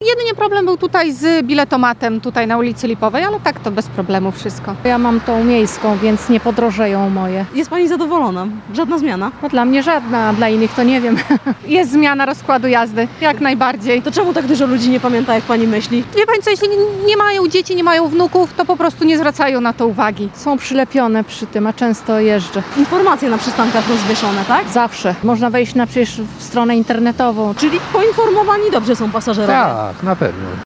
Z sondy, którą przeprowadziliśmy wśród mieszkańców, wynika, że od rana nie było zaskoczenia nowym rozkładem jazdy.